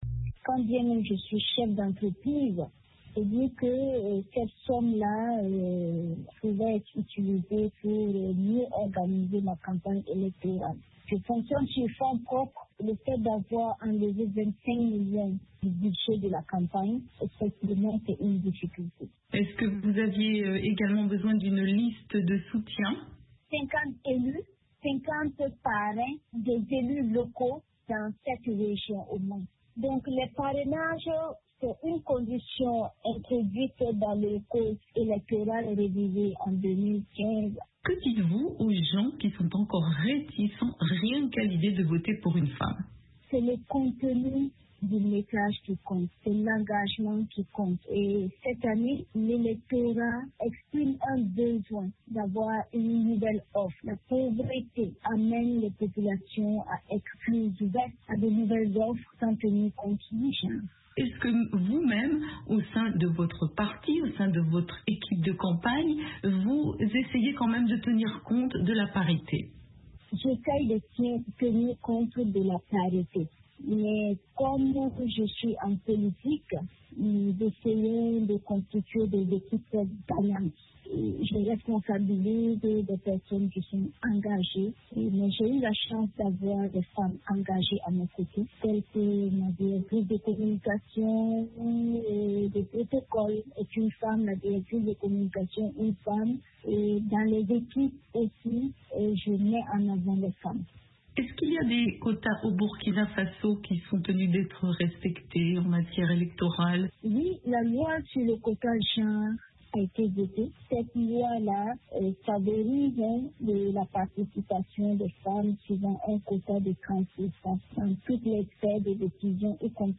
Entretien avec Yeli Monique Kam, candidate à la présidentielle au Burkina Faso